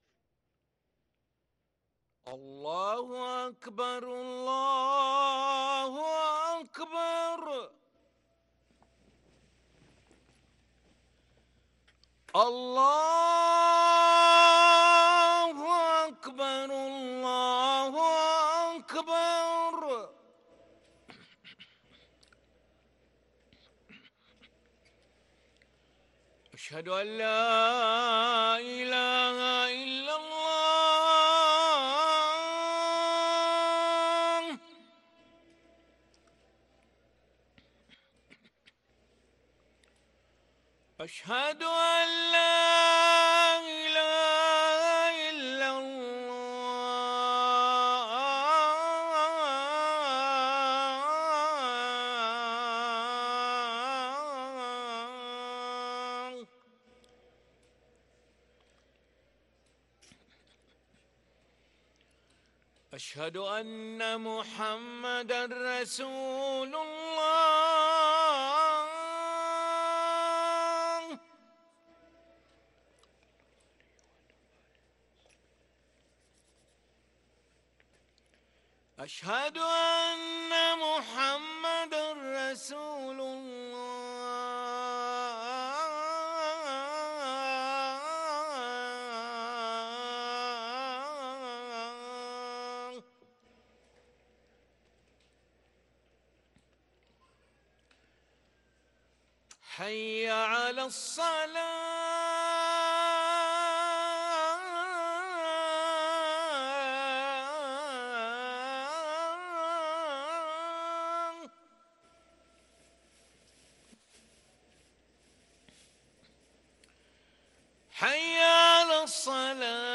أذان العشاء للمؤذن علي ملا الخميس 30 ربيع الآخر 1444هـ > ١٤٤٤ 🕋 > ركن الأذان 🕋 > المزيد - تلاوات الحرمين